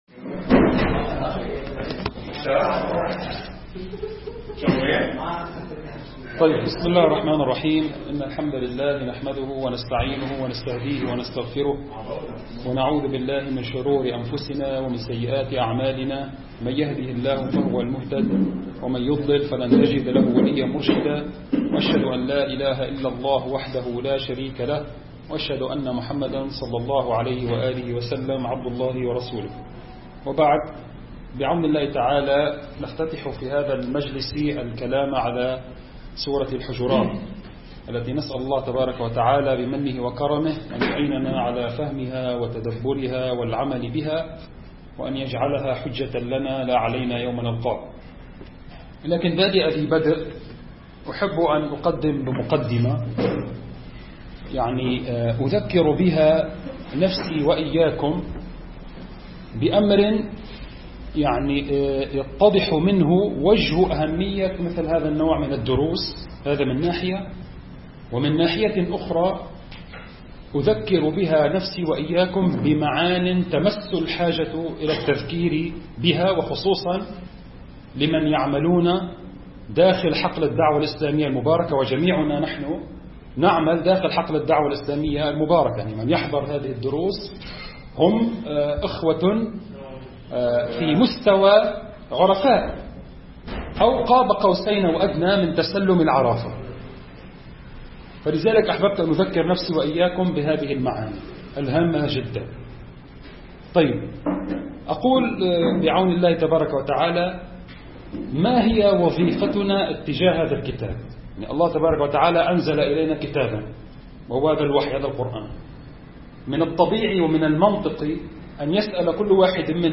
المكان: مركز جماعة عباد الرحمن